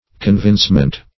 Search Result for " convincement" : The Collaborative International Dictionary of English v.0.48: Convincement \Con*vince"ment\, n. Act of convincing, or state of being convinced; conviction.